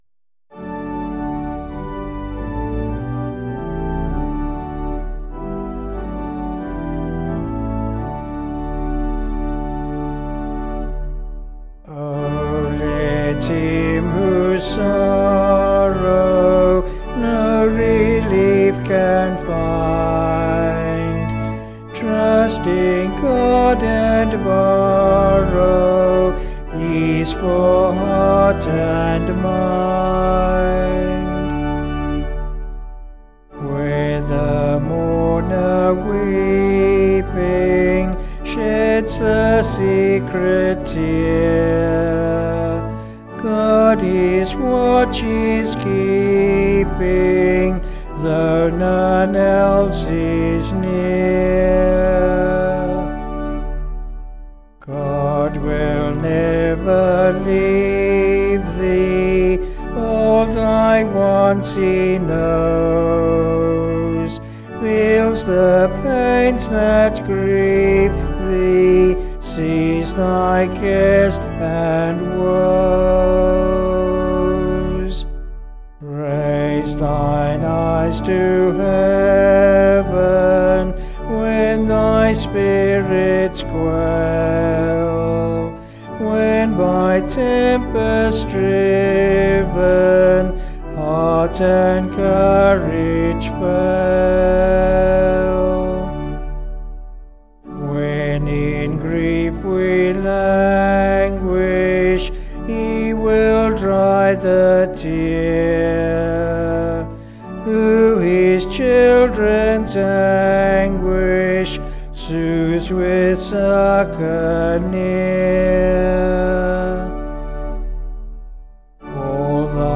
Vocals and Organ   264.1kb Sung Lyrics